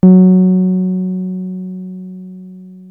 303 F#3 6.wav